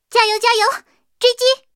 BT-2夜战语音.OGG